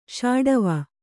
♪ ṣāḍava